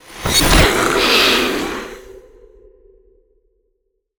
dark_magic_conjure_blast_2.wav